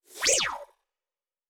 Synth Whoosh 2_1.wav